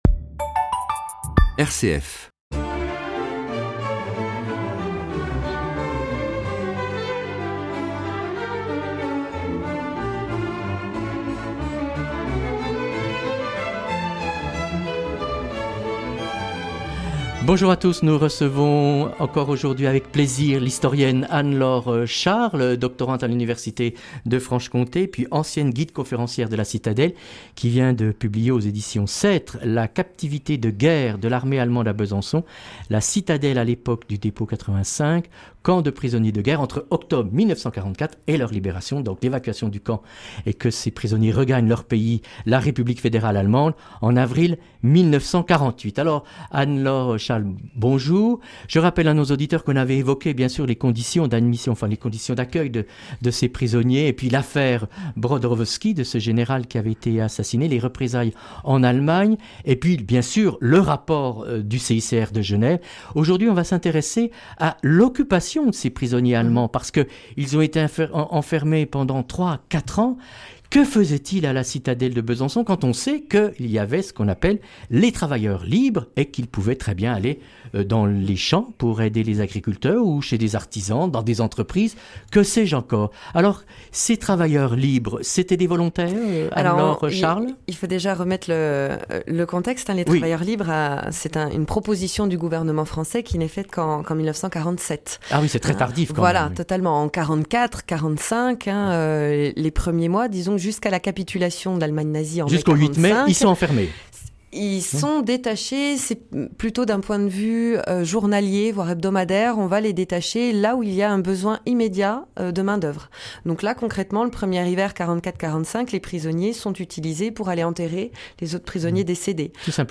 Vous retrouverez les 2 interviews ci-dessous :